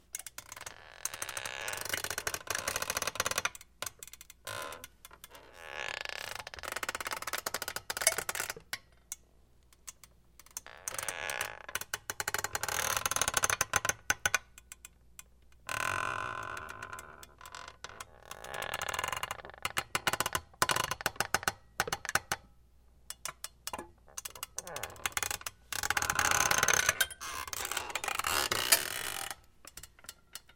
爆米花
描述：爆米花在微波炉中弹出。
Tag: 现场录音 持久性有机污染物 食品